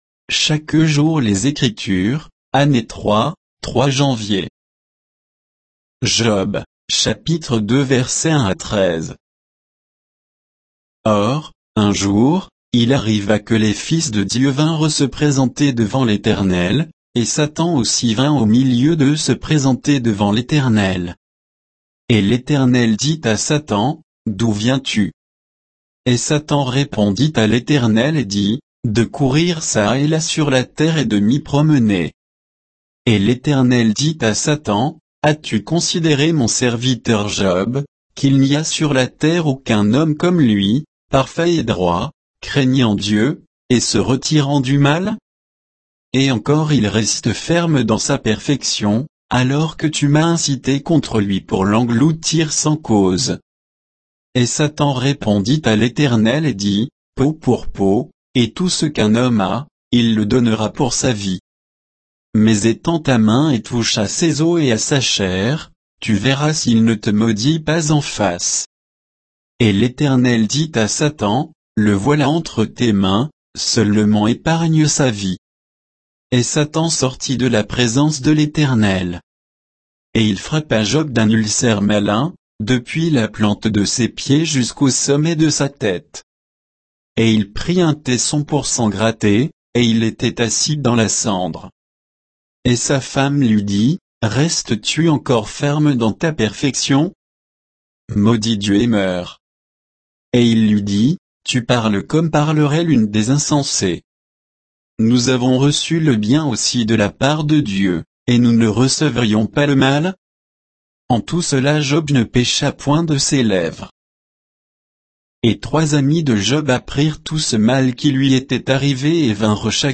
Méditation quoditienne de Chaque jour les Écritures sur Job 2, 1 à 13